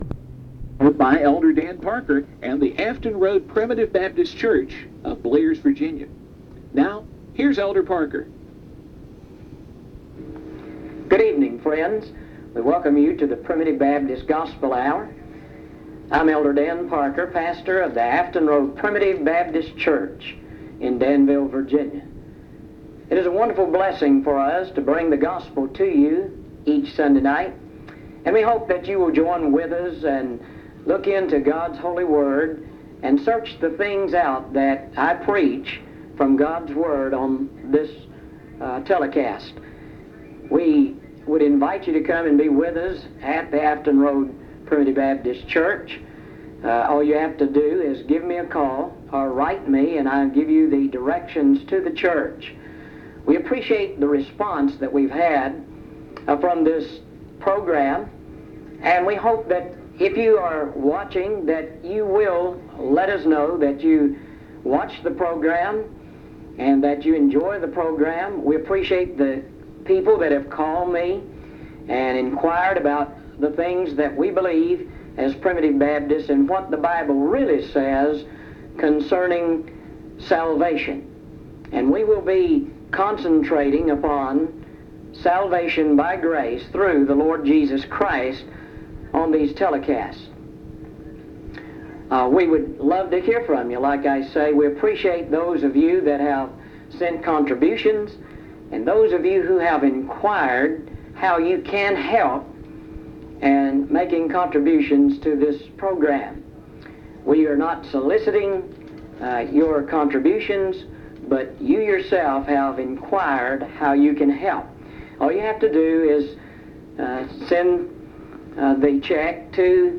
Acts 2; Recording from a broadcast